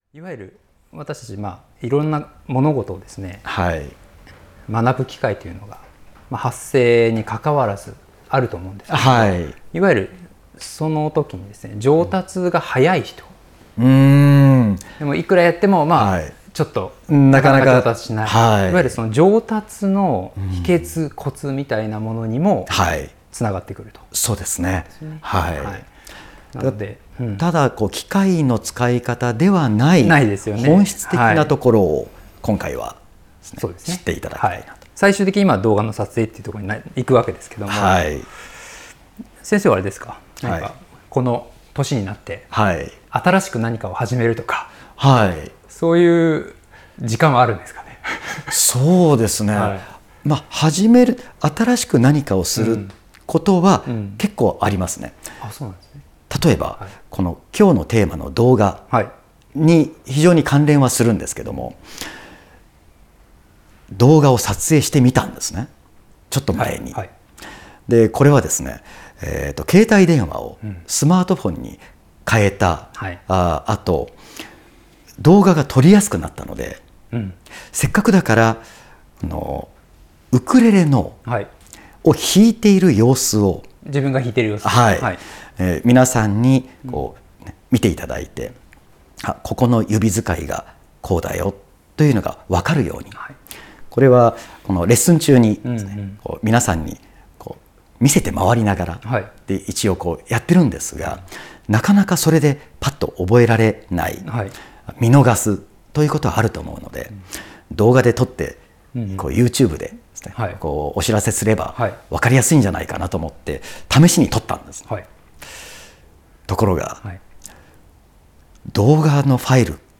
音声講座